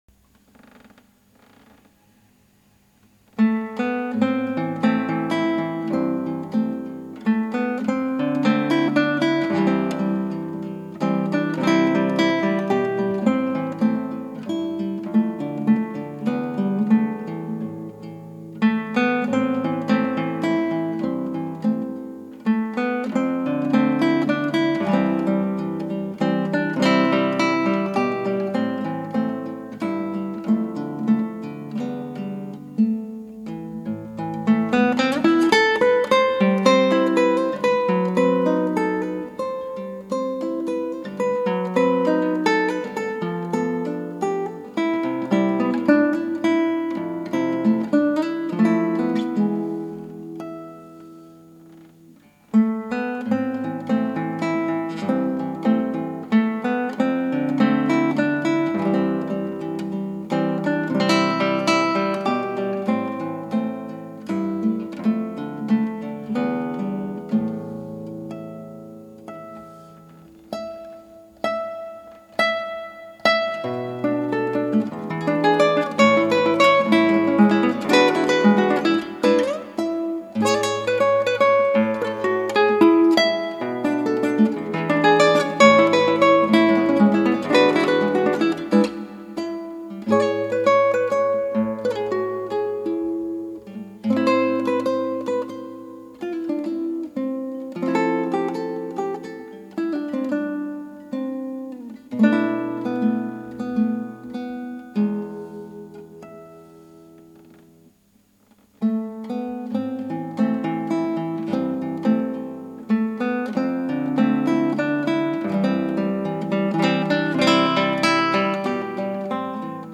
シンプルで美しい曲です